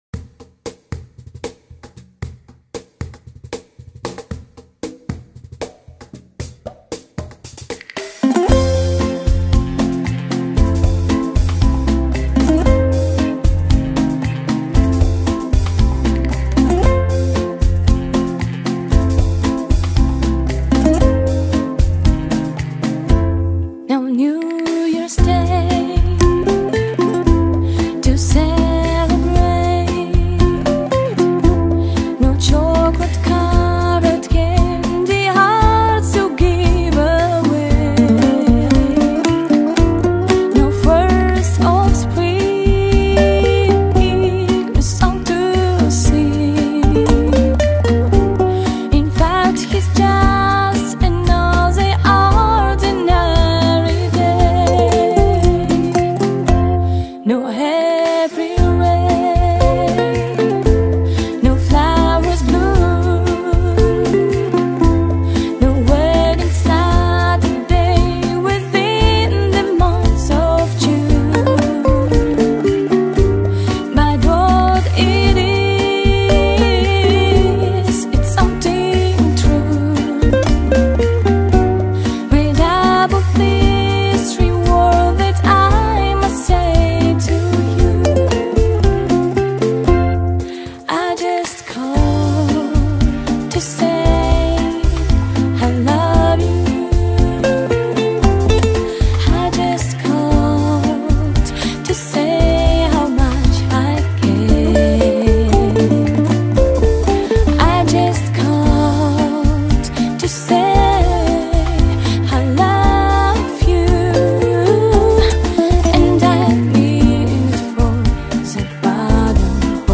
это трогательный и мелодичный трек в жанре соул и поп.